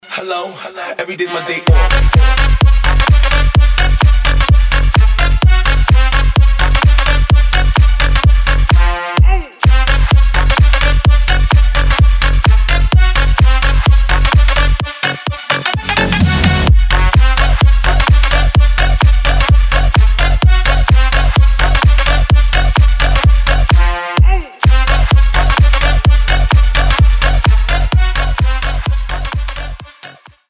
• Качество: 320, Stereo
мужской вокал
dance
Electronic
club
Future Bounce